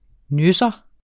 Udtale [ ˈnøsʌ ]